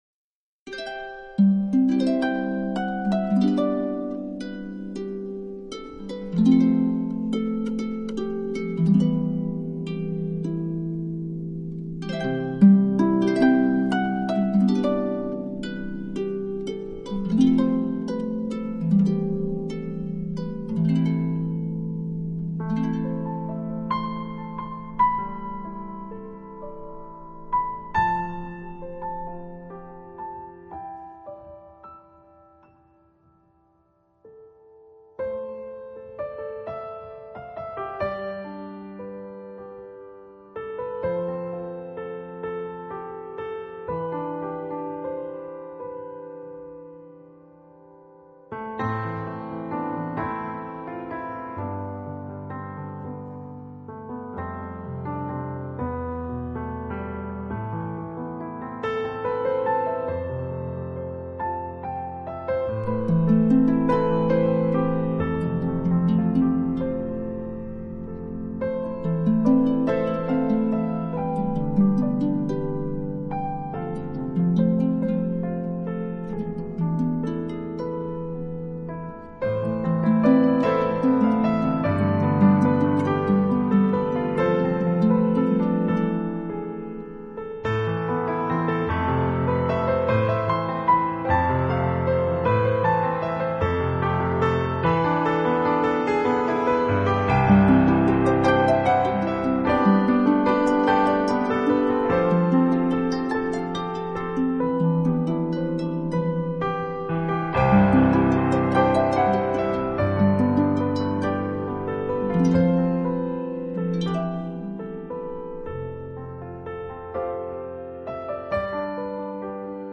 内心最深处的那种柔媚，可以让所有的聆听者都为之微笑，呵，让人心神俱醉的钢琴哪……